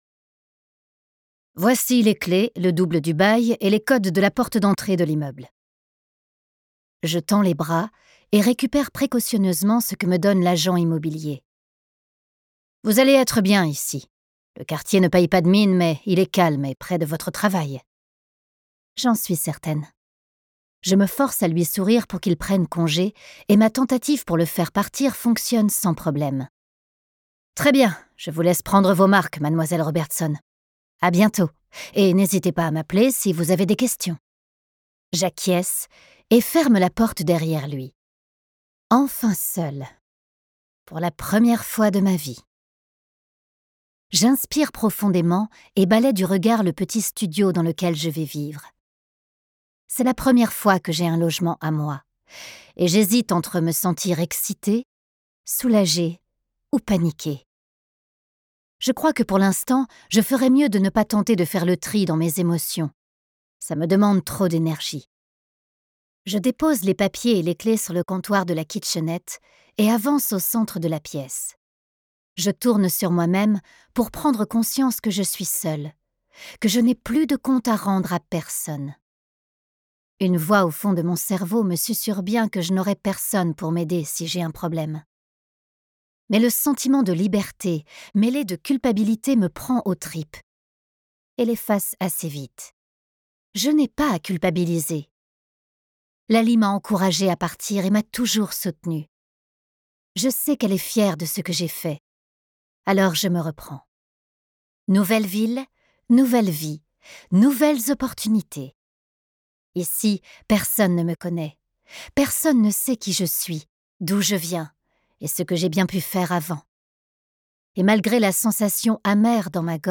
Un secret partagé, une romance lu par un duo de comédiens... Une attirance dangereuse et un geste de trop.